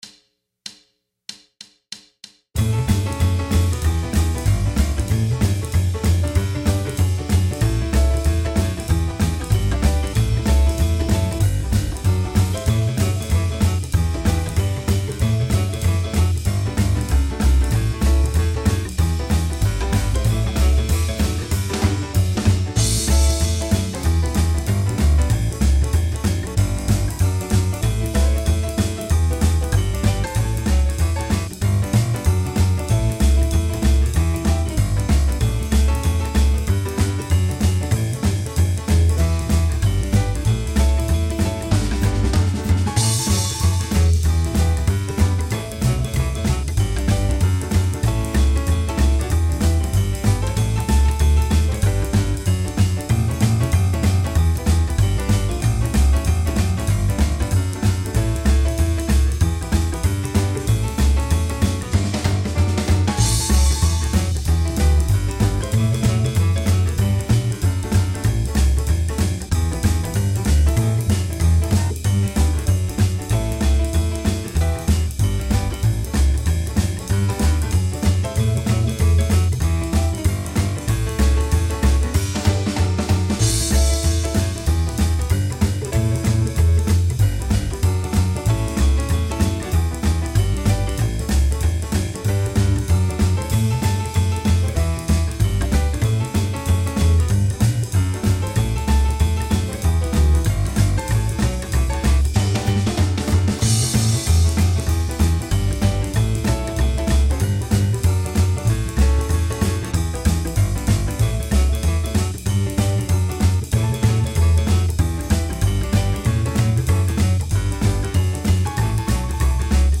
mode-5-mixolydian-root-A.mp3